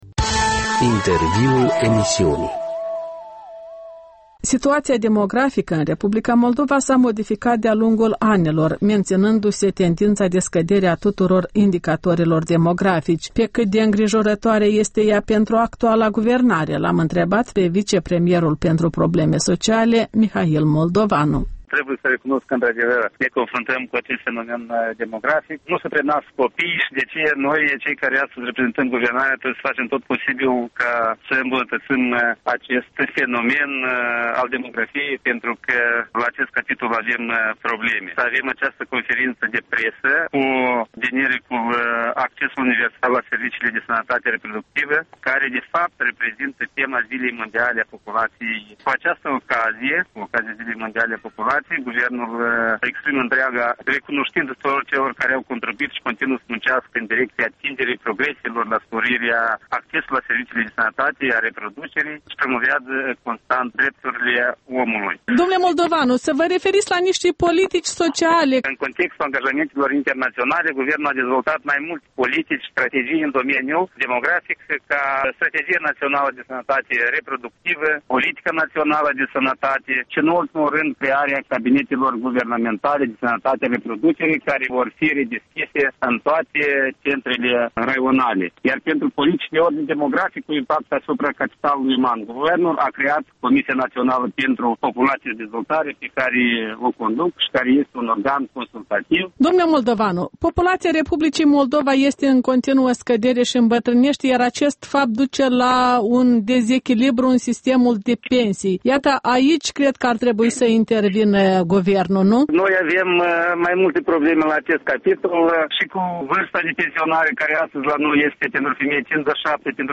Un interviu cu vicepremierul Mihail Moldovanu despre dificultățile de ordin demografic ale Moldovei